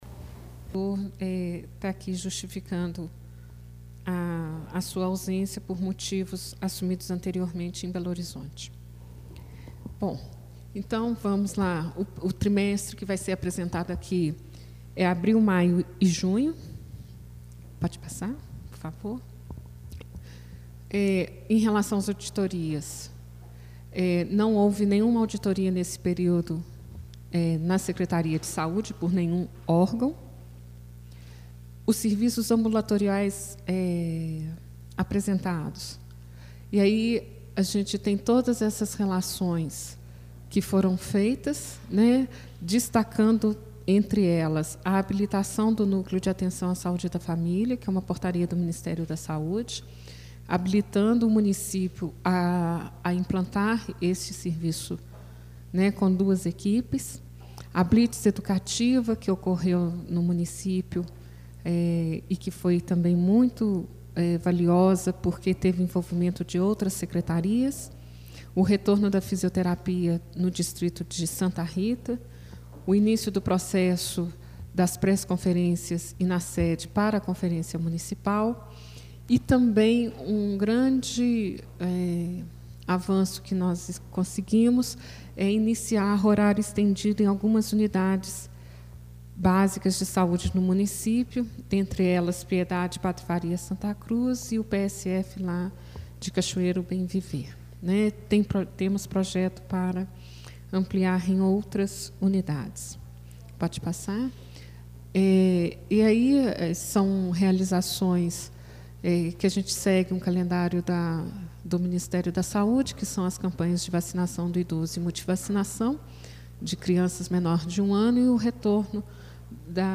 Áudio: | Câmara Municipal de Ouro Preto Audiência Pública para a 2ª prestação de contas trimestral da secretaria municipal de saúde, referente aos meses de abril, maio e junho de 2011 Reunião Compartilhar: Fechar